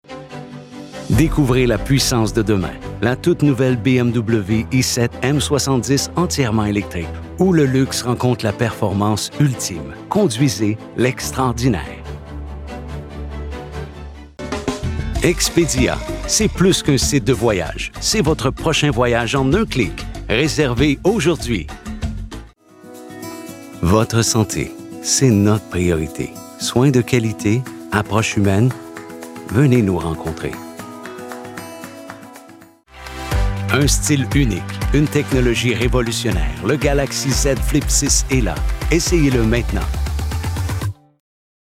French Canadian Voice Talent